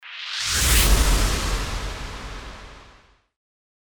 FX-1533-WIPE
FX-1533-WIPE.mp3